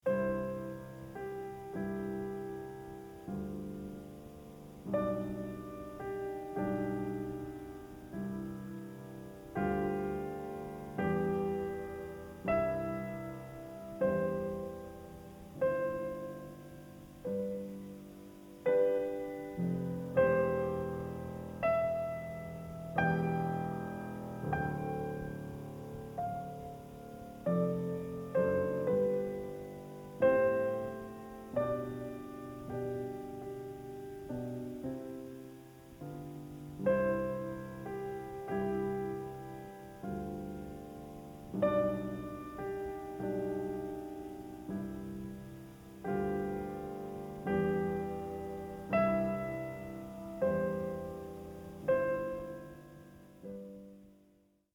Bi-channel/Stereo
Adagio molto semplice e cantabile